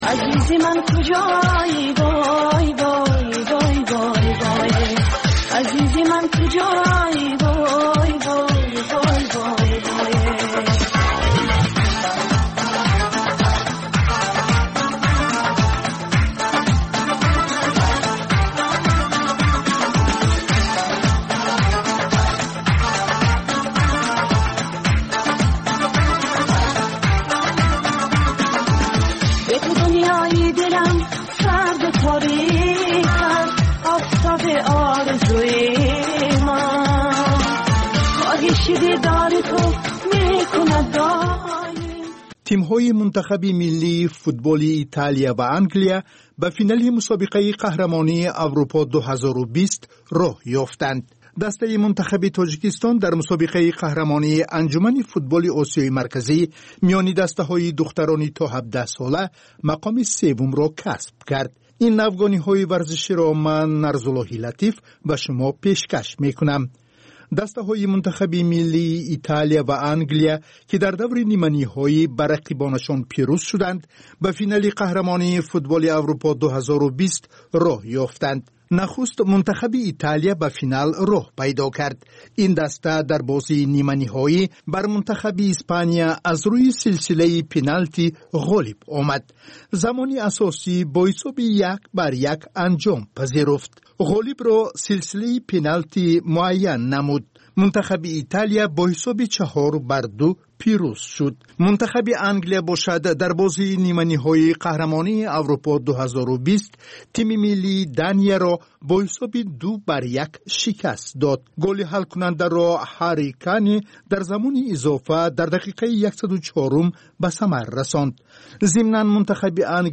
Тозатарин ахбор ва гузоришҳои марбут ба Тоҷикистон, минтақа ва ҷаҳон дар маҷаллаи шомгоҳии Радиои Озодӣ.